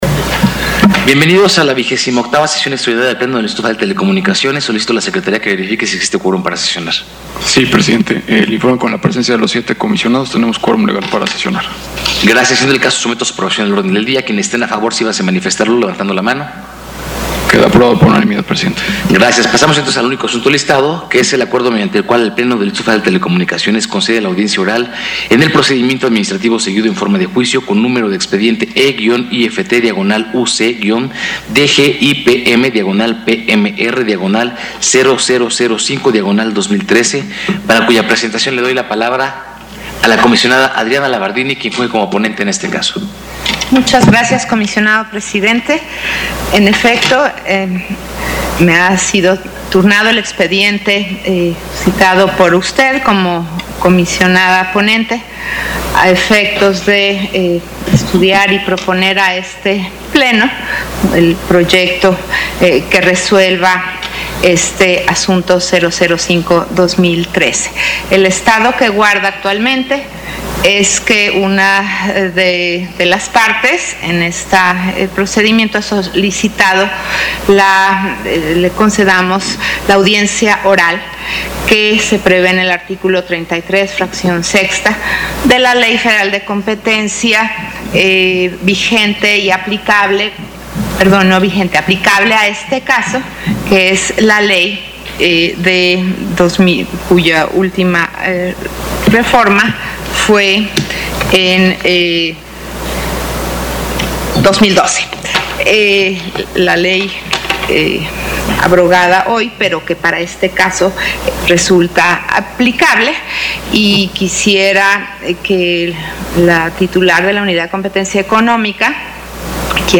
XXVIII Sesión Extraordinaria del Pleno 20 de agosto de 2015